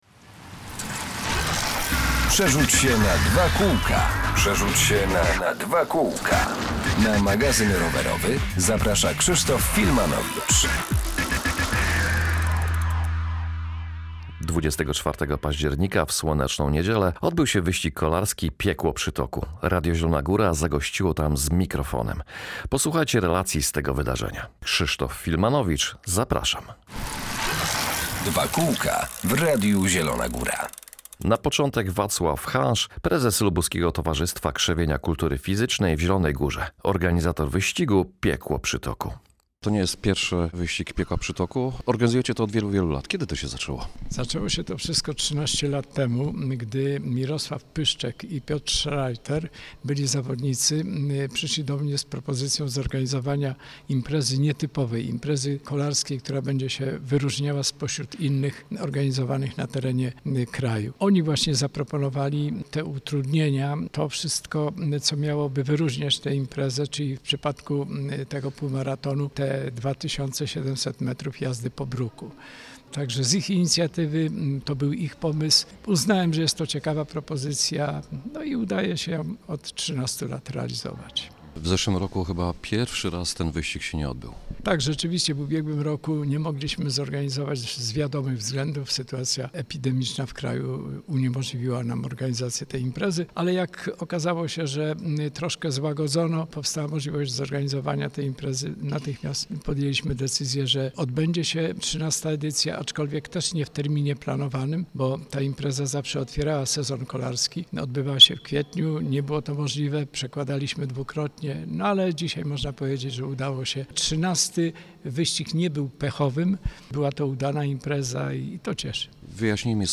Relacja z wyścigu kolarskiego „Piekło Przytoku” /24.10.2021 r./.